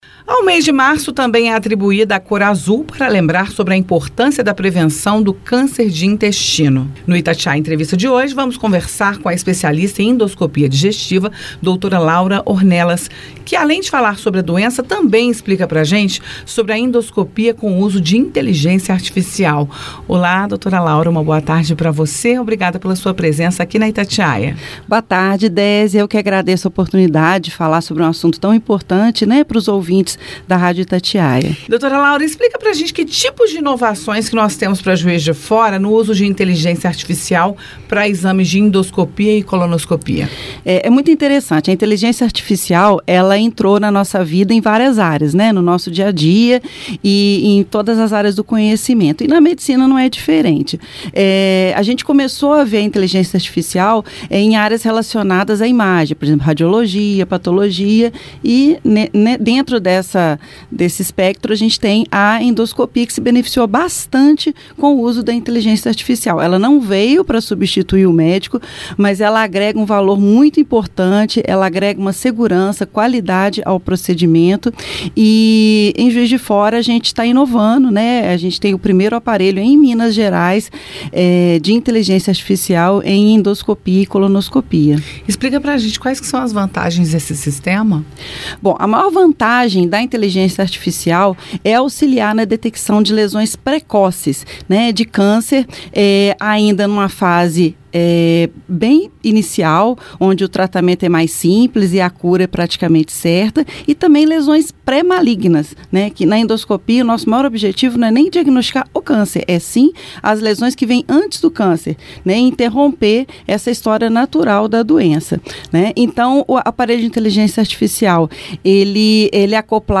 No Itatiaia Entrevista